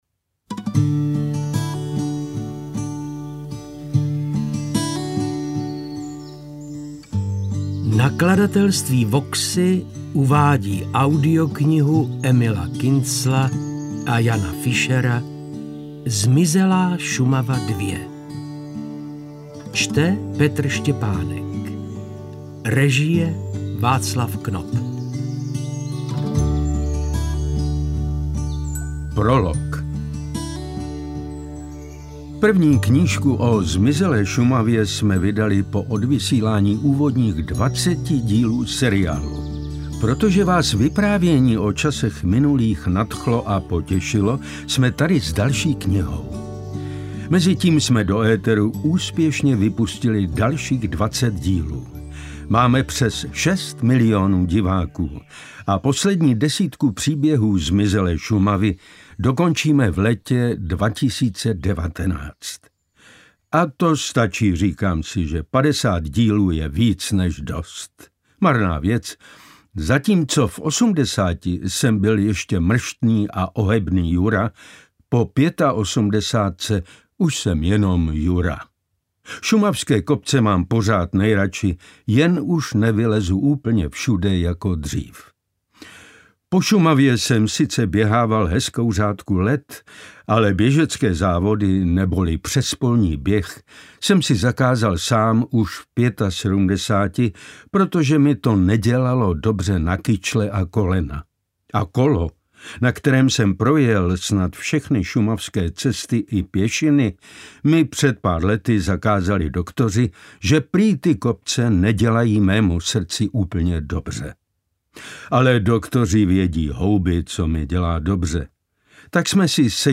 Interpret:  Petr Štěpánek
AudioKniha ke stažení, 20 x mp3, délka 2 hod. 33 min., velikost 138,9 MB, česky